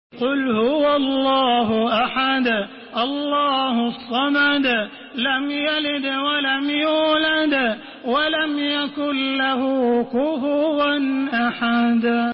Surah Al-Ikhlas MP3 in the Voice of Makkah Taraweeh 1432 in Hafs Narration